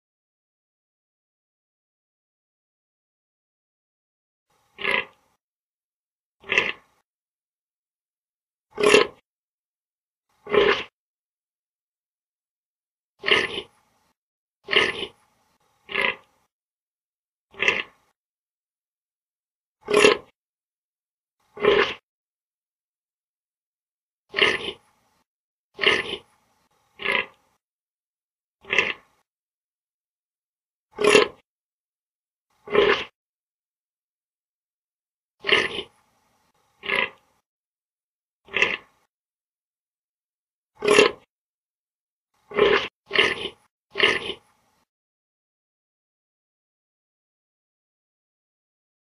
Sonido del Gamo Sonidos cortos de animales.mp3